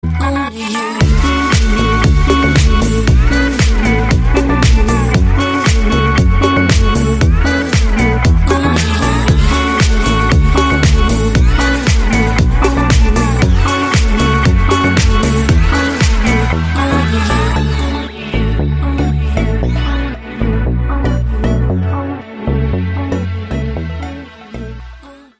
ремиксы